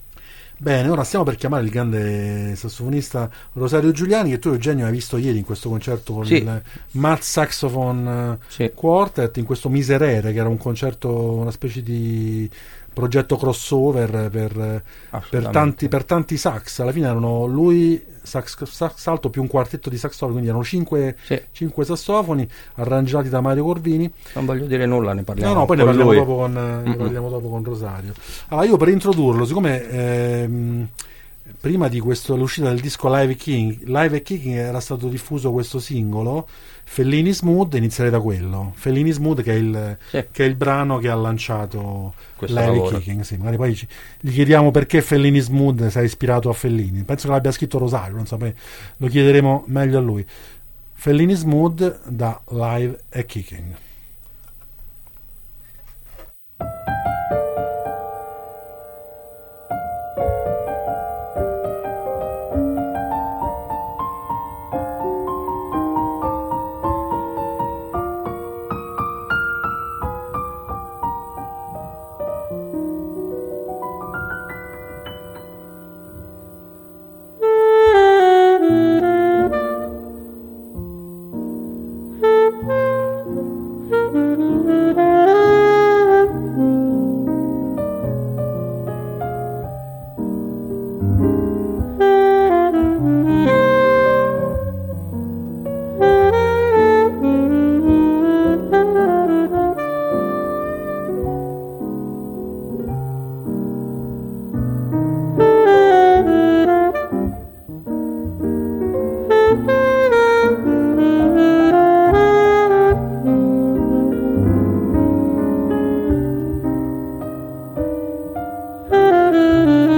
Intervista a Rosario Giuliani (“Drive in Saturday” del 7/12/2024) | Radio Città Aperta